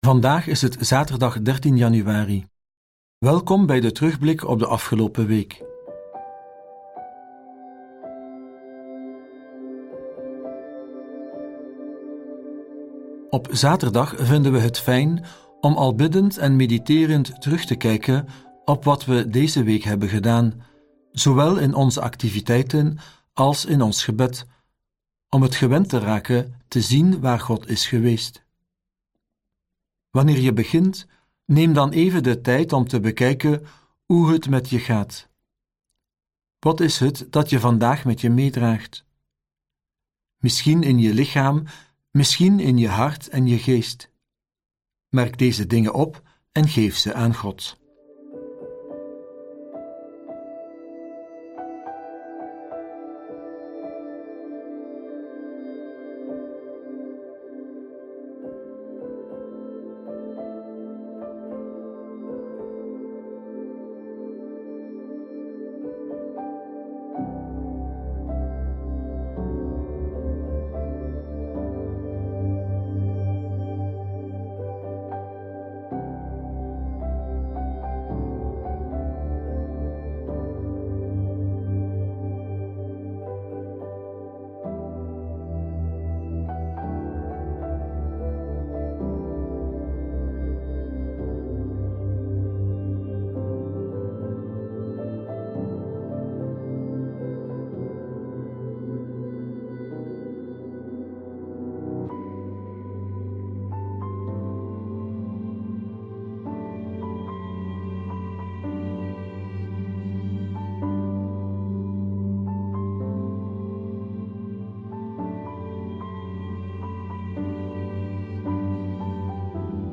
De muzikale omlijsting, overwegingen y begeleidende vragen helpen je om tot gebed te komen.